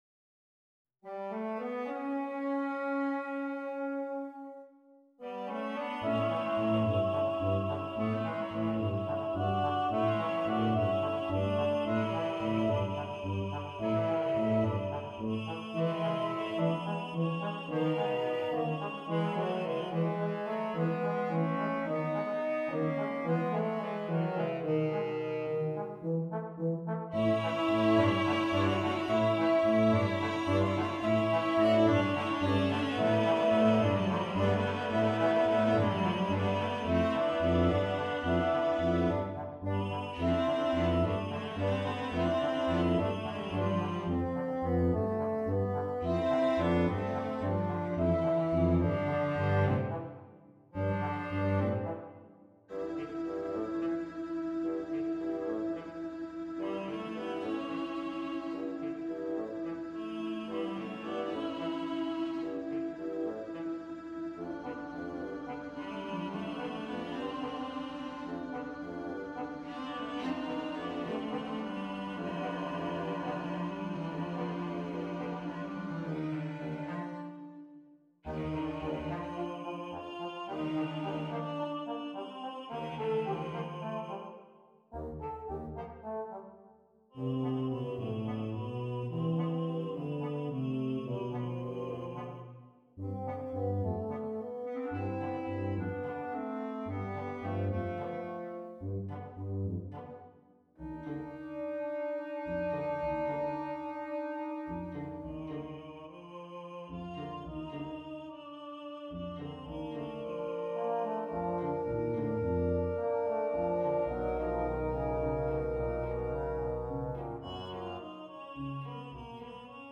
song cycle